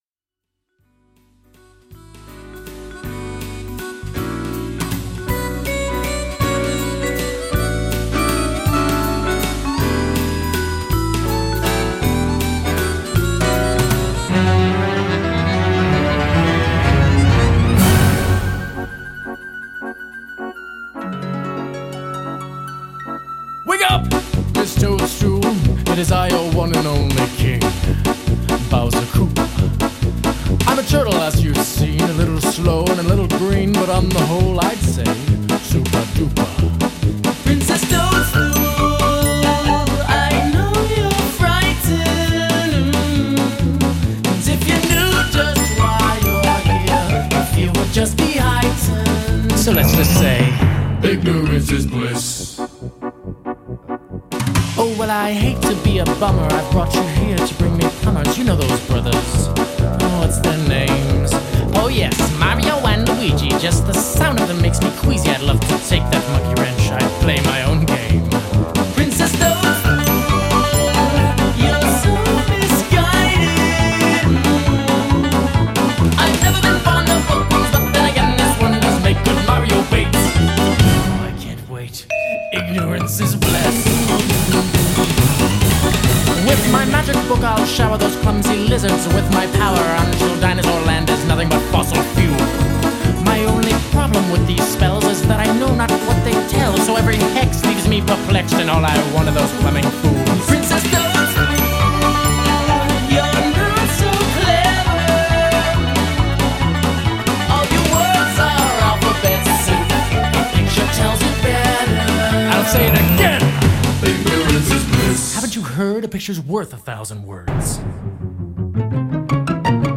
power poppers